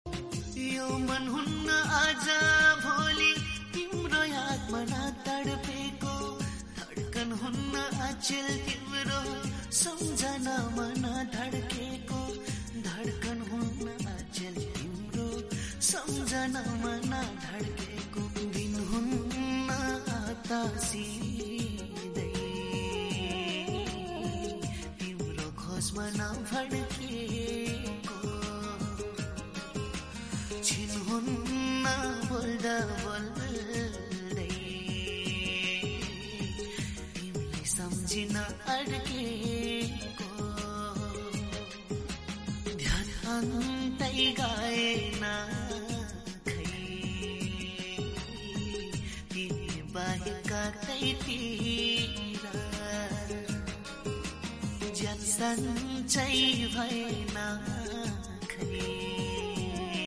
Voice Cover By myself